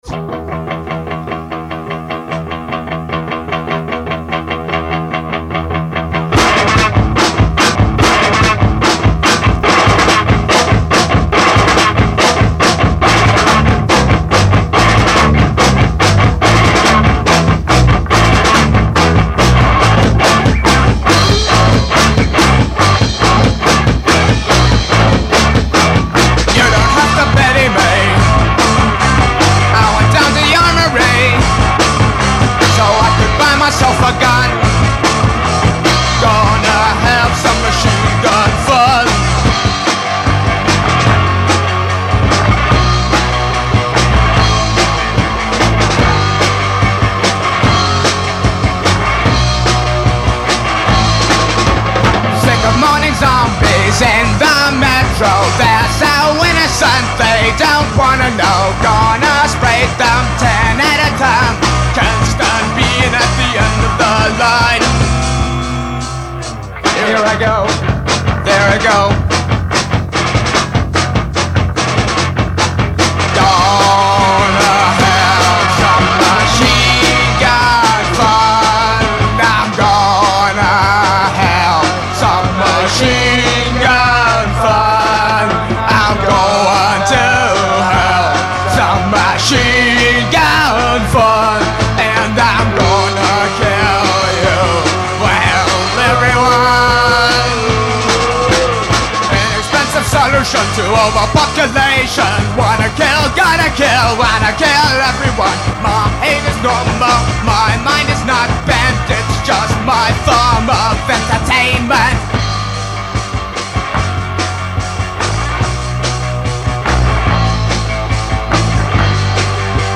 I was really into Jimi Hendrix, so his song Machine Gun most likely influenced my guitar on this (he tried to imitate the sound of a machine gun).
vocals
guitar